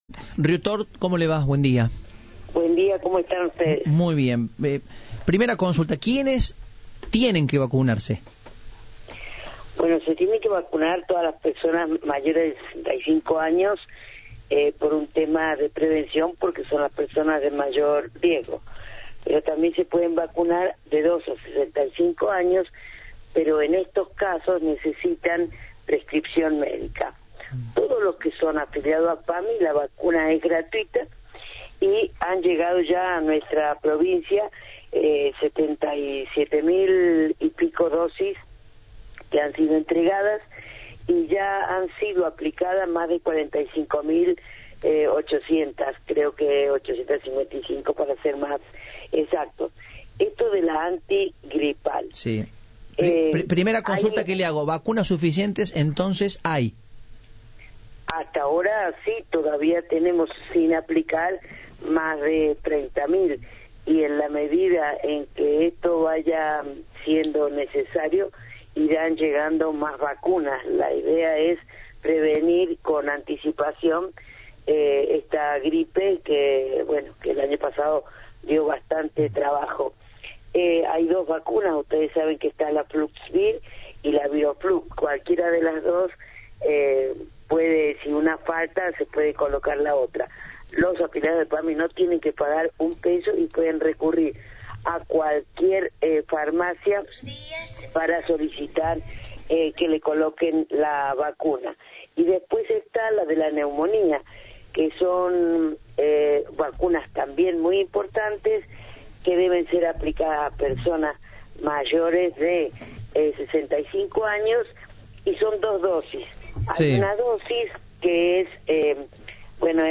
En diálogo con Cadena 3, la titular del Pami Córdoba, Olga Riutort dijo que se tienen que colocar la vacuna todas las personas mayores de 65 años "por un tema de prevención" y las menores de 65 años, bajo prescripción médica.
Entrevista